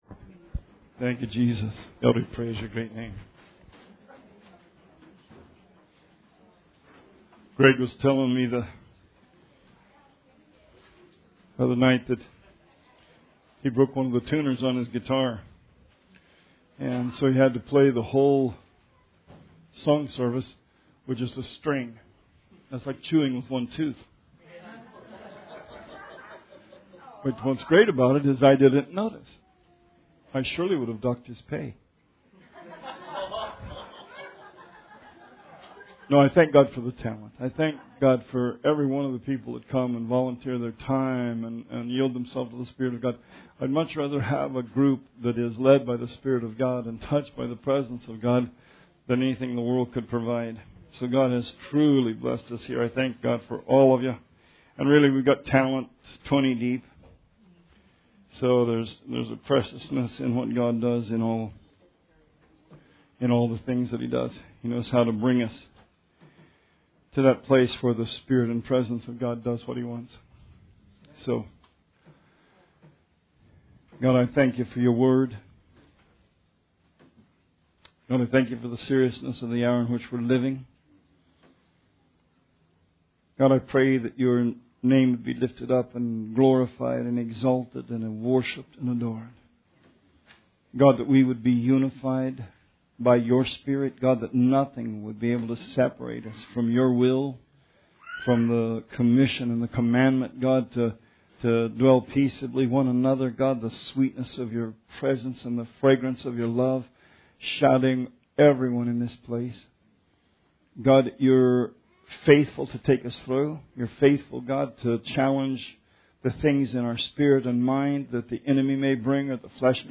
Sermon 8/5/18